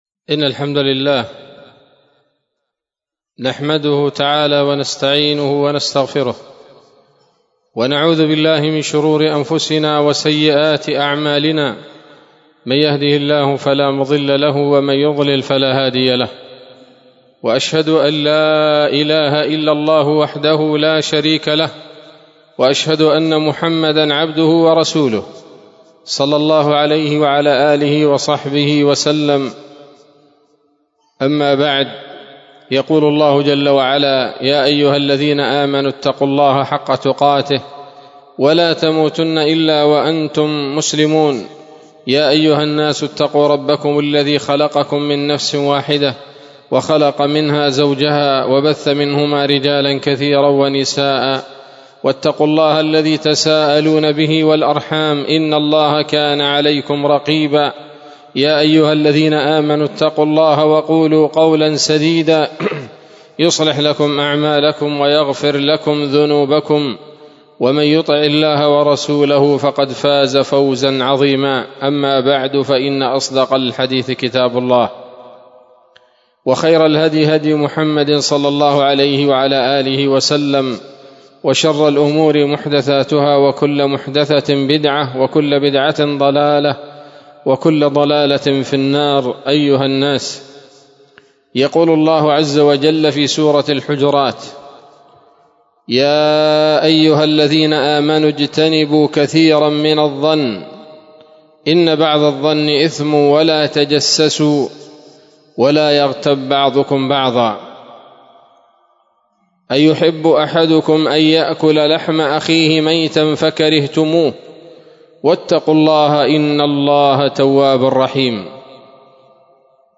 خطبة جمعة بعنوان: (( الذم والخيبة لأرباب الغيبة )) 23 جمادى الأولى 1447 هـ، دار الحديث السلفية بصلاح الدين